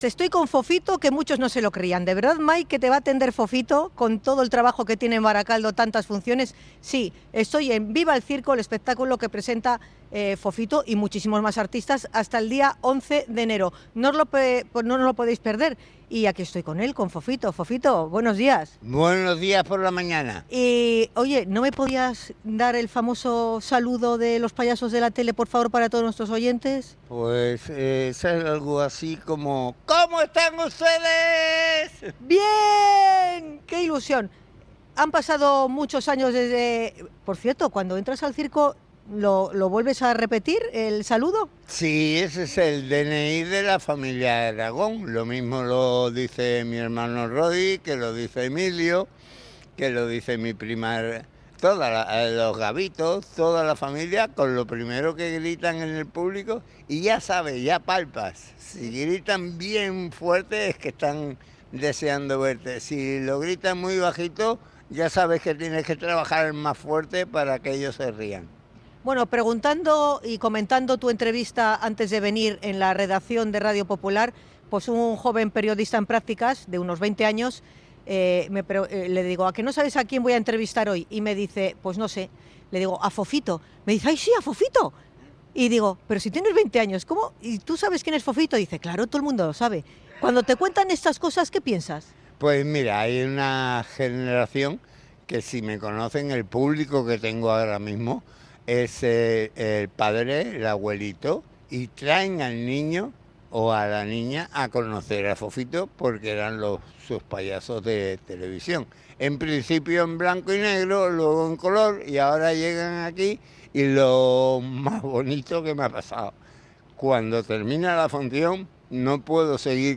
Fofito nos ha atendido en la carpa del circo instalado en Barakaldo
INT.-VIVA-EL-CIRCO-FOFITO.mp3